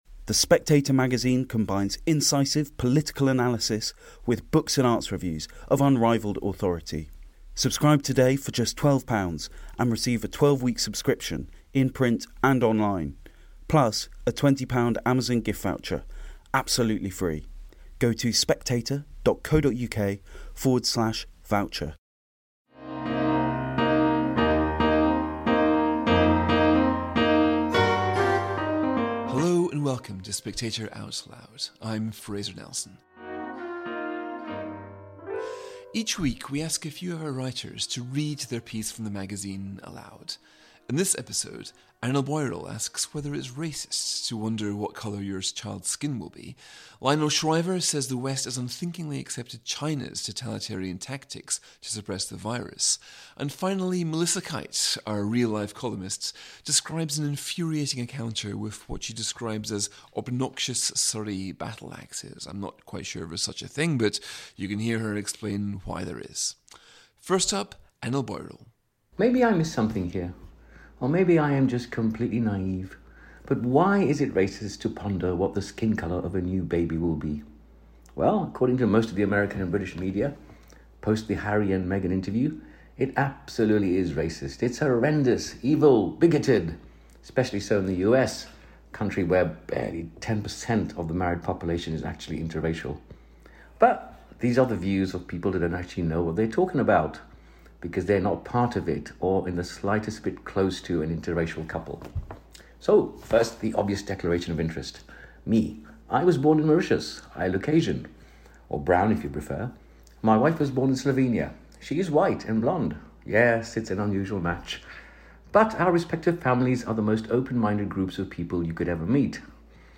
Each week, we ask a few of our writers to read their piece from the magazine aloud.